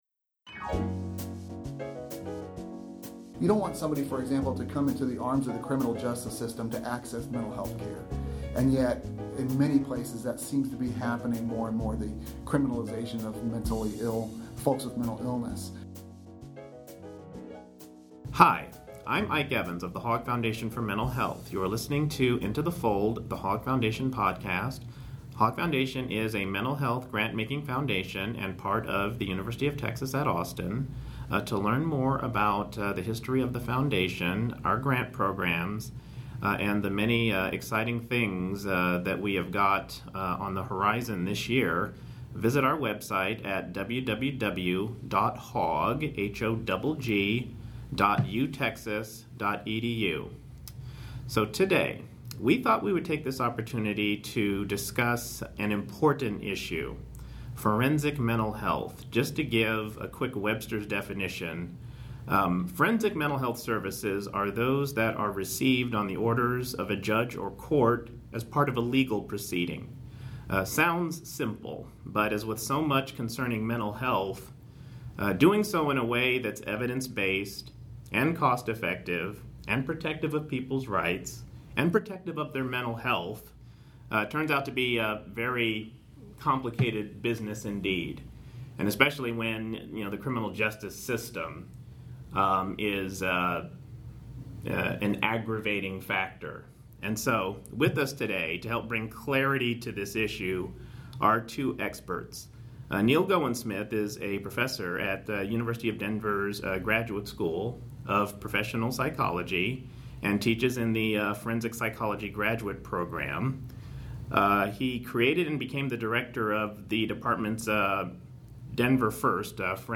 have an in-depth conversation on forensic mental health, how to do it better, and ways that the judicial and mental health systems can interface in a more cost-effective and humane way.